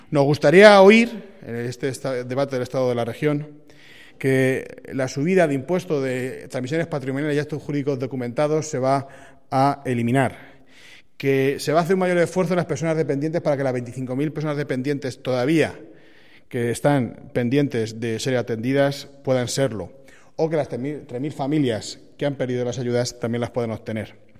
Así se ha expresado el diputado en una rueda de prensa en la que ha estado acompañado por los también diputados provinciales Juan Gómez y Pablo Escobar. Constantino Berruga ha afirmado que este debate es “esperado y necesario”, y que los albaceteños tienen ilusiones depositadas en él, para comprobar si el presidente Page cumplirá con su palabra.
cortedevoz.constantinoberrugasobrede(1).mp3